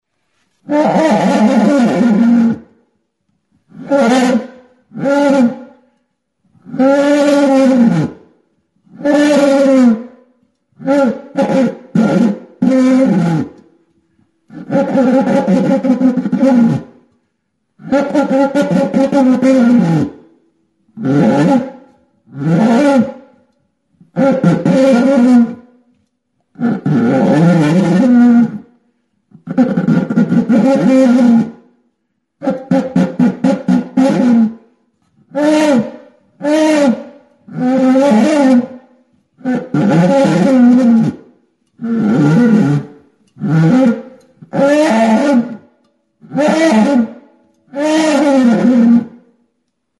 Enregistré avec cet instrument de musique.